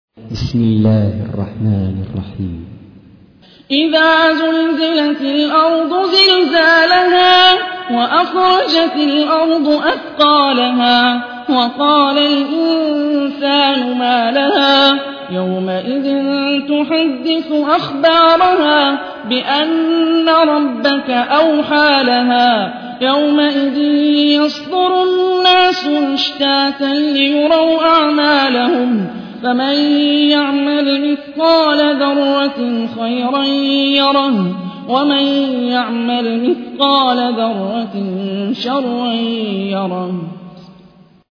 سورة الزلزلة / القارئ هاني الرفاعي / القرآن الكريم / موقع يا حسين